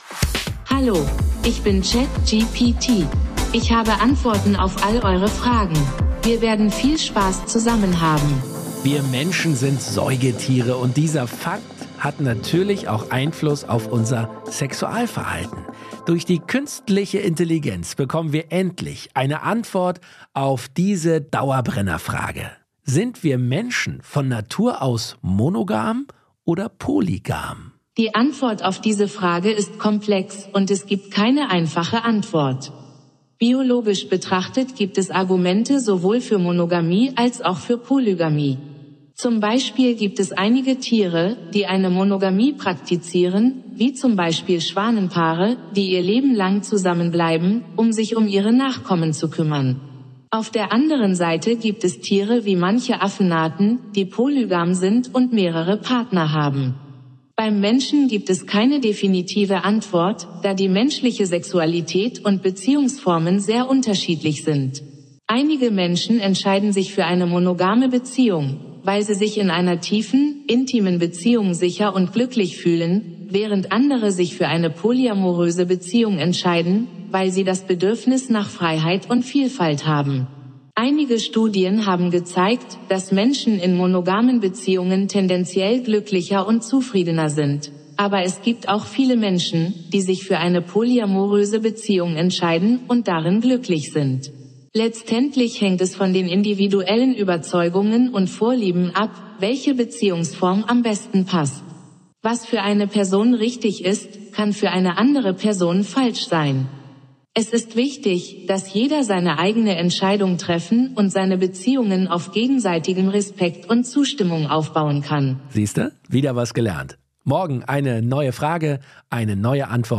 Künstlichen Intelligenz ChatGPT von OpenAI als Co-Host.